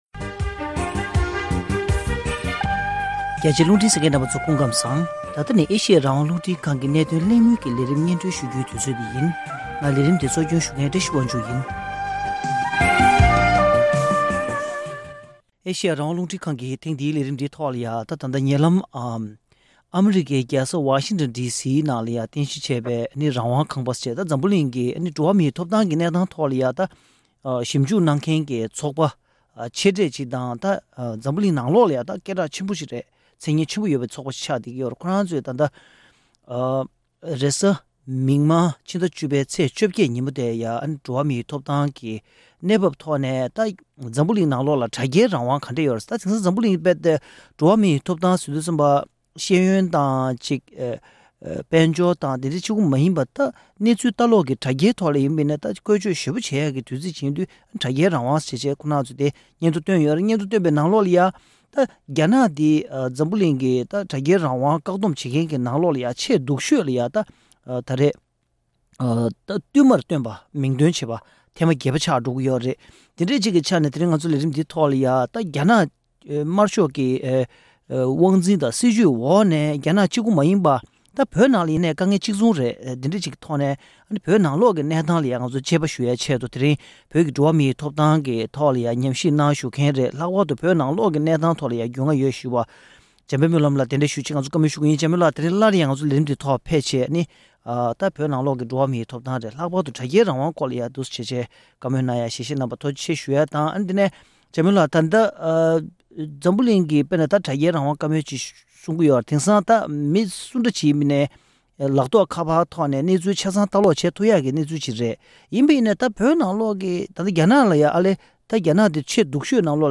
དེ་རིང་གནད་དོན་གླེང་མོལ་གྱི་ལས་རིམ་ནང་།